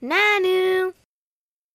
nanu_sound.mp3